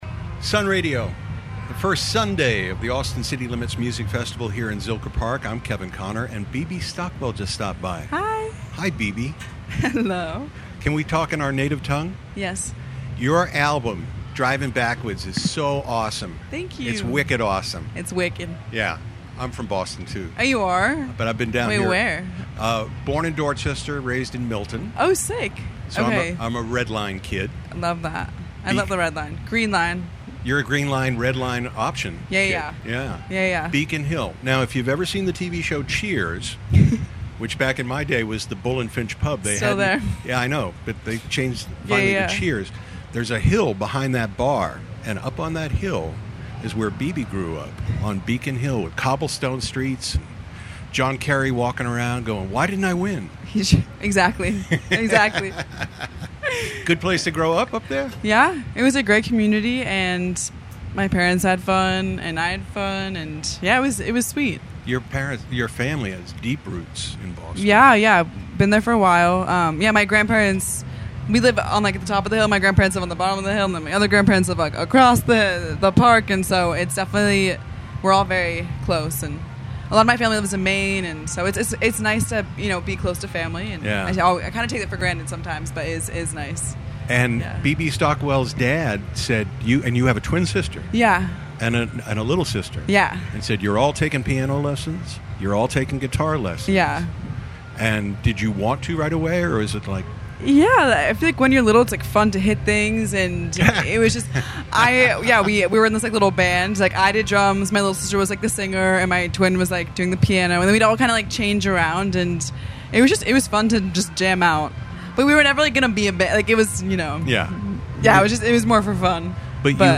When he wasn’t running around the field catching amazing bands play live, he interviewed them in the Sun Radio Solar Powered Saloon!